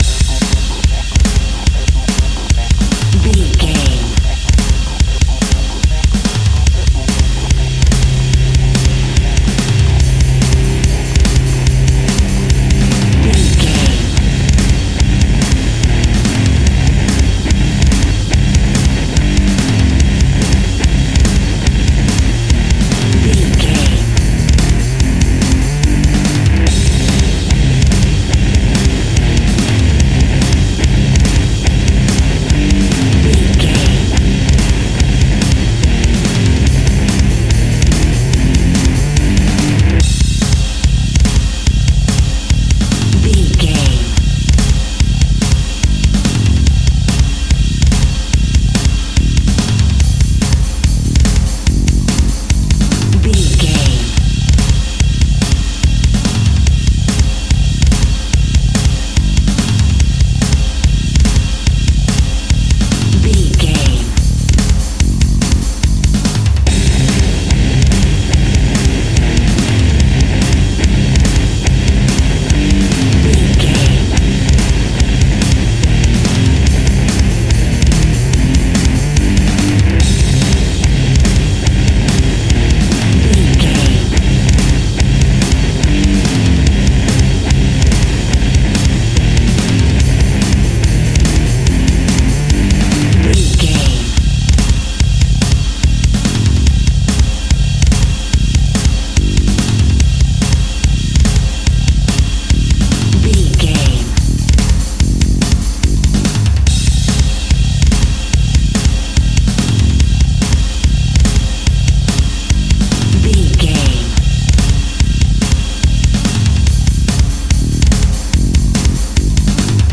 Epic / Action
Fast paced
Aeolian/Minor
hard rock
heavy metal
distortion
Rock Bass
Rock Drums
heavy drums
distorted guitars
hammond organ